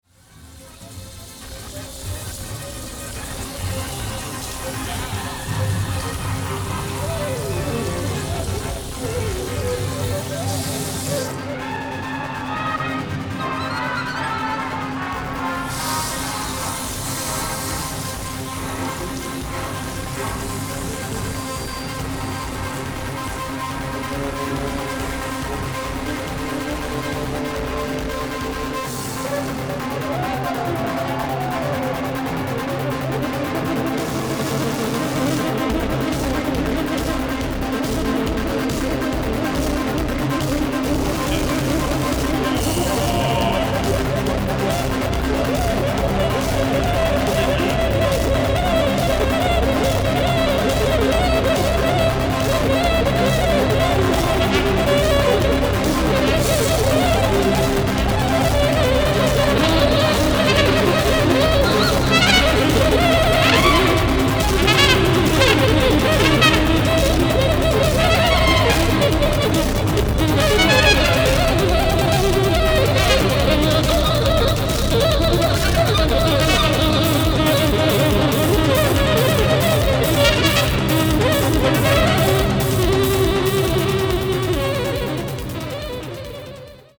Alto Saxophone, Synthesizer [Juno 6], Percussion, Voice
Bagpipes